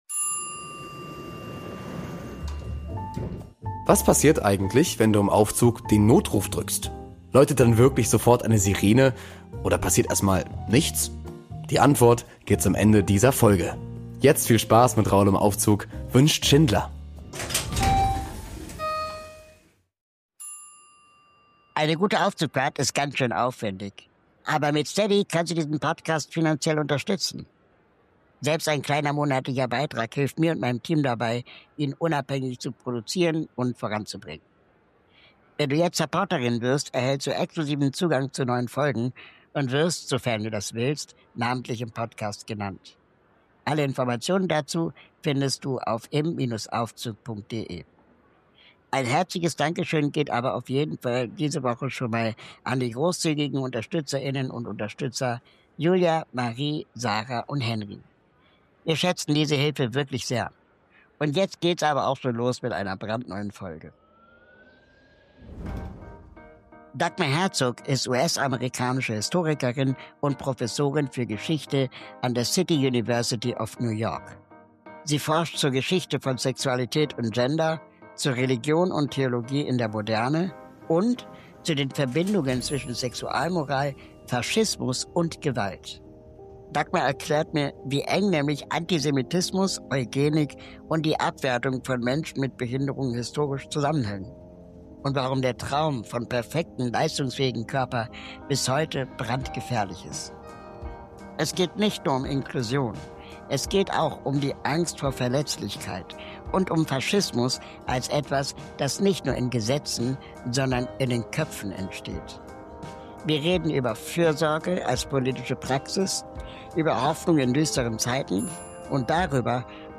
Ein Gespräch, das bei mir noch lange nach klang.